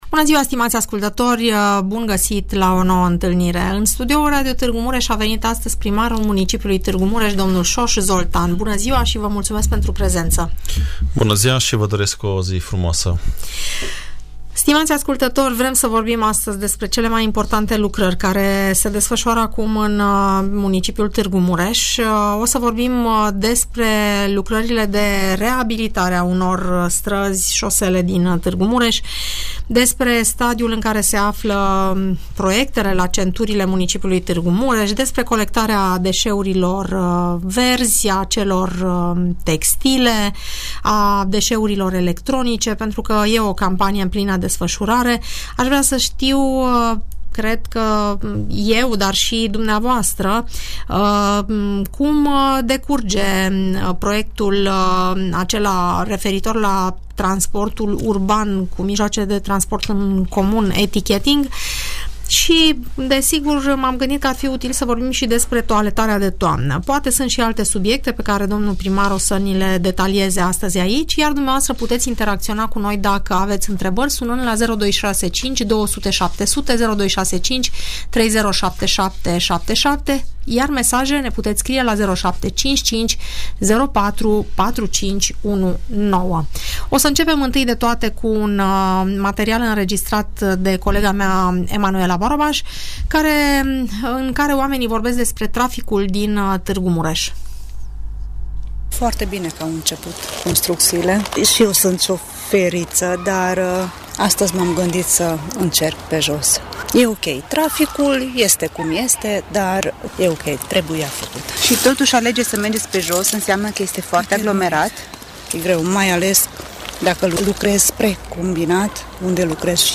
Primarul mun. Tg Mureș, Soós Zoltán la Radio Tg.Mureș - Radio Romania Targu Mures